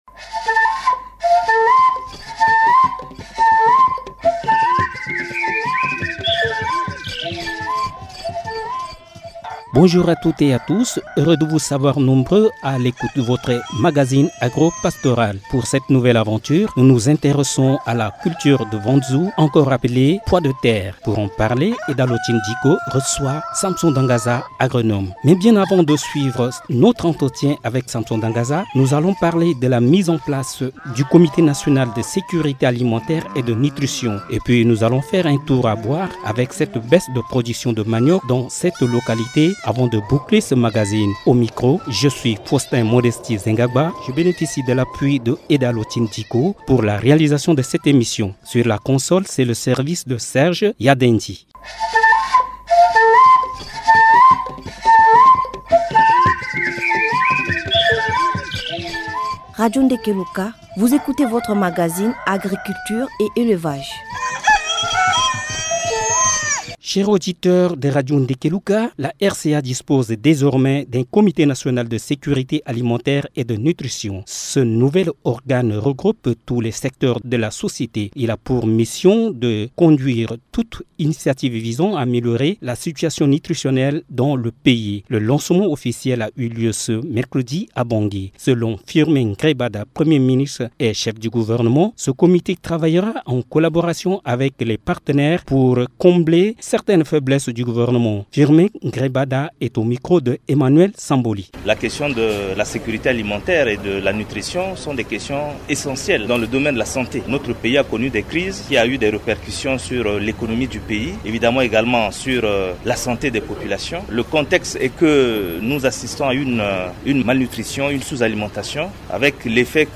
Pour en savoir plus, cliquez sur le bouton ci-dessous pour écouter le reportage et l’entretien avec notre invité.